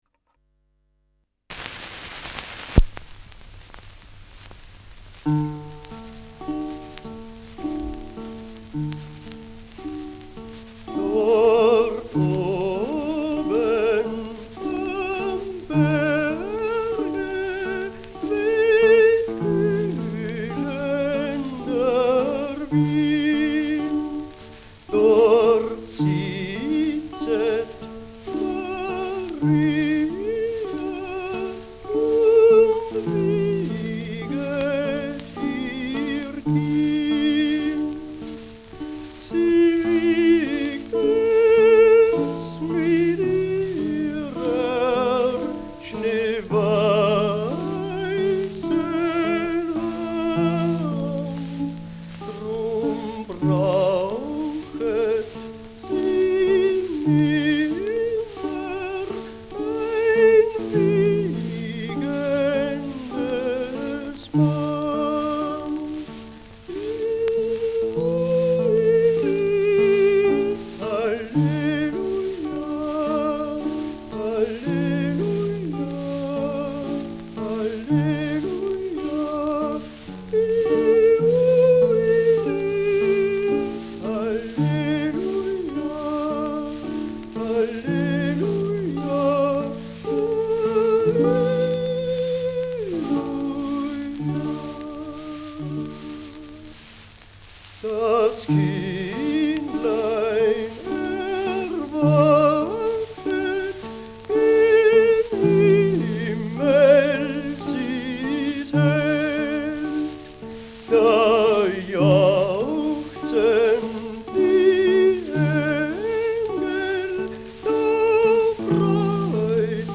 Schellackplatte
Vereinzelt leichtes Knacken : Leiern : Nadelgeräusch : Gegen Ende Pfeifton im Hintergrund